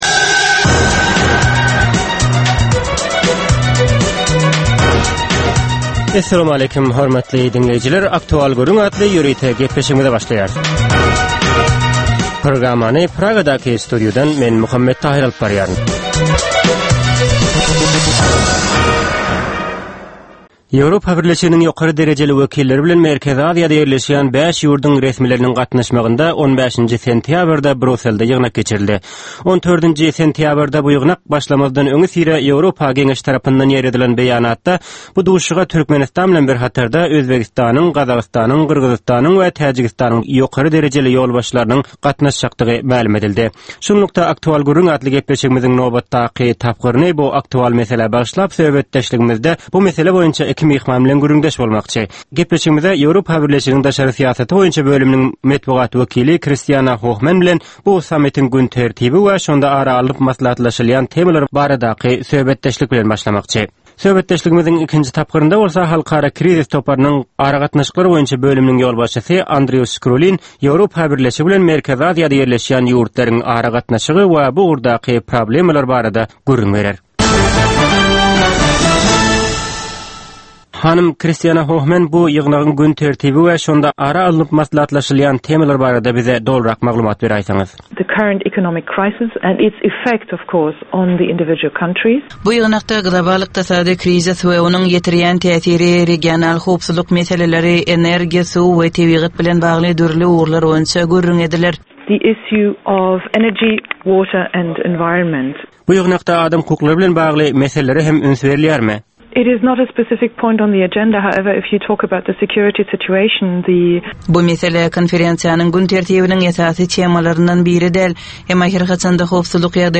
Hepdäniň dowamynda Türkmenistanda ýa-da halkara arenasynda ýüze çykan, bolup geçen möhüm wakalar, meseleler barada anyk bir bilermen ýa-da synçy bilen geçirilýän 10 minutlyk ýörite söhbetdeşlik. Bu söhbetdeşlikde anyk bir waka ýa-da mesele barada synçy ýa-da bilermen bilen aktual gürrüňdeşlik geçirilýär we meseläniň dürli ugurlary barada pikir alyşylýar.